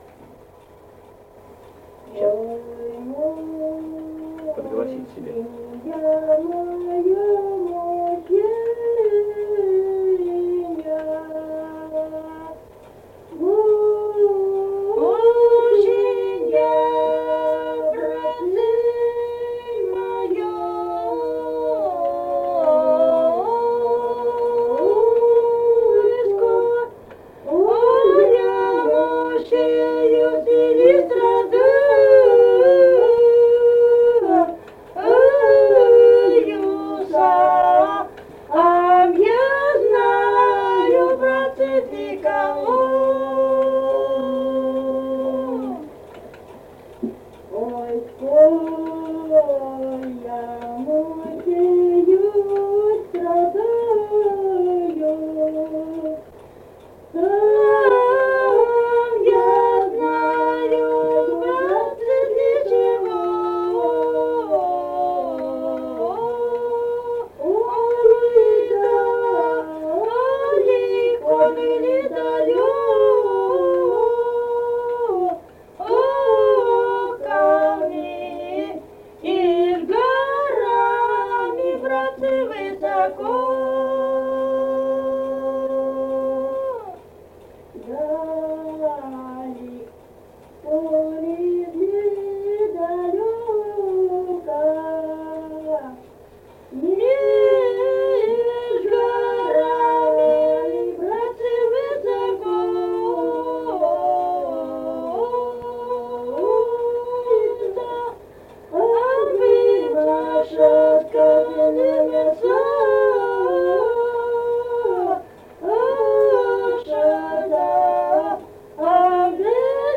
Русские песни Алтайского Беловодья 2 «Ой, мученье моё, мученье», лирическая.
Республика Казахстан, Восточно-Казахстанская обл., Катон-Карагайский р-н, с. Белое, июль 1978.